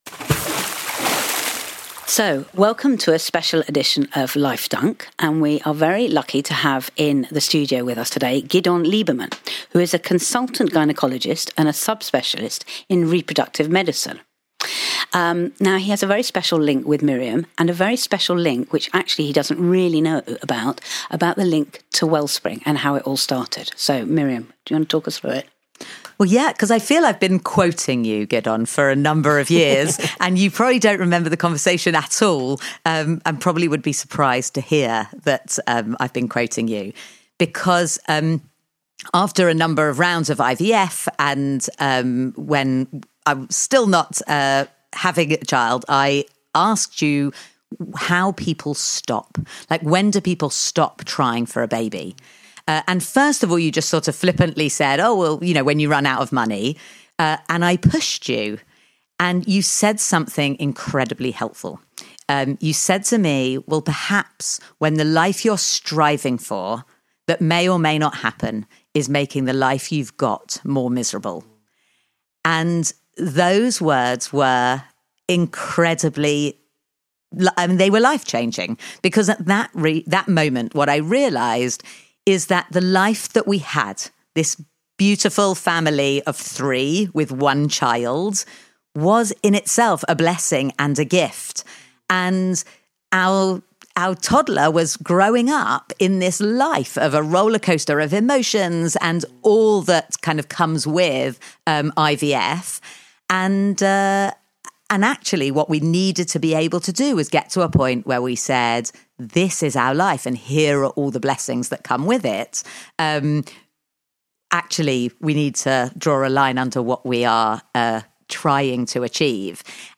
A personal and extremely honest conversation